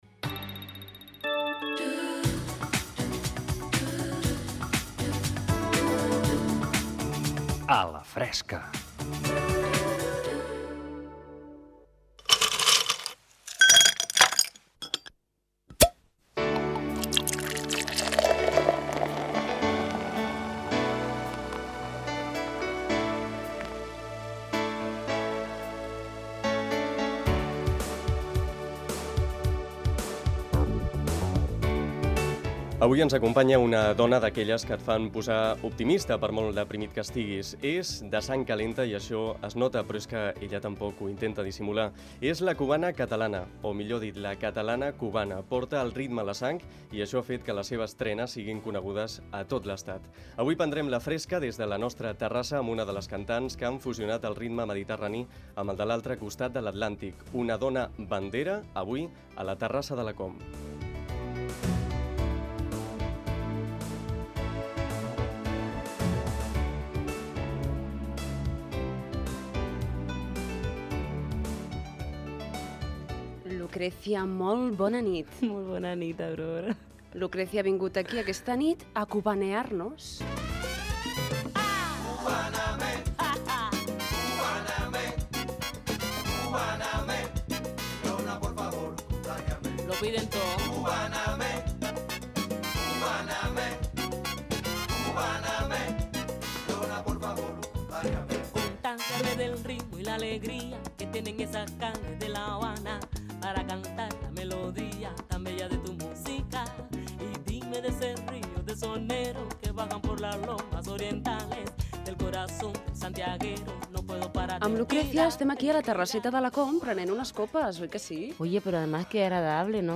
Careta del programa, presentació i fragment d'una entrevista a la cantant Lucrecia
Entreteniment